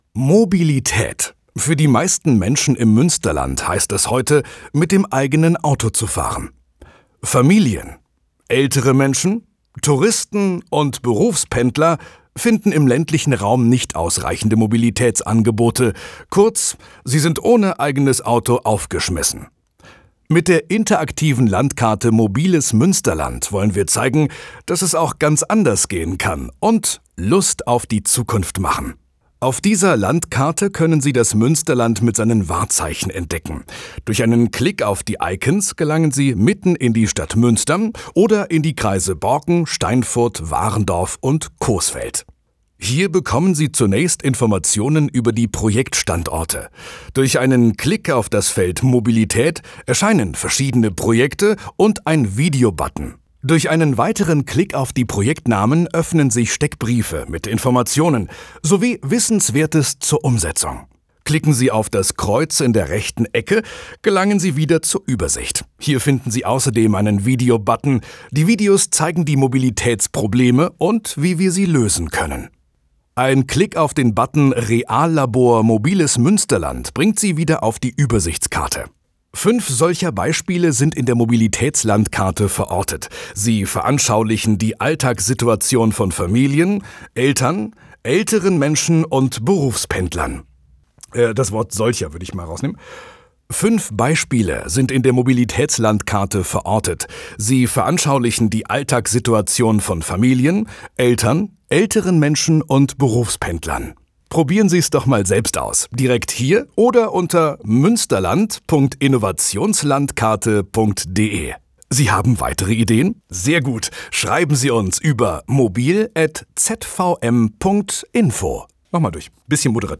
Mobiles Münsterland - Off Text (sendefertig komprimiert) Mobiles Münsterland